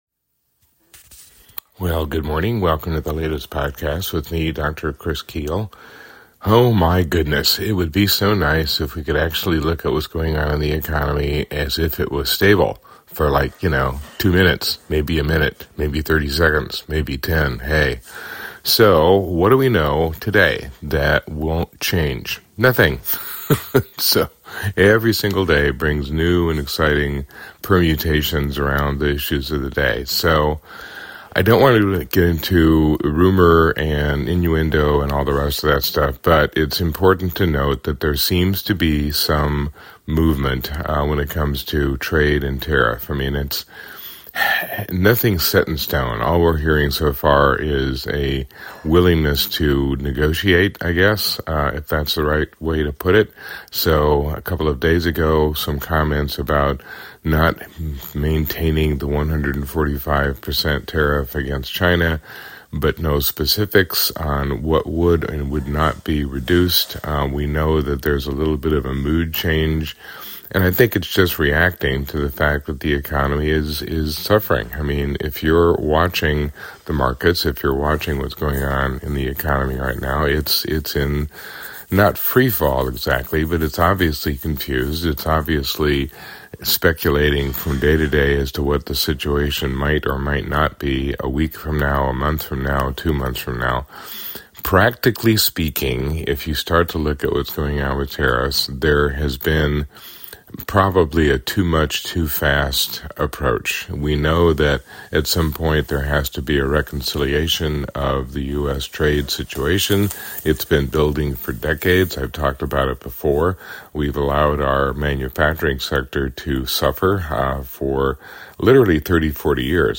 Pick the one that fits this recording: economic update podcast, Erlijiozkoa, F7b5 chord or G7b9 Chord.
economic update podcast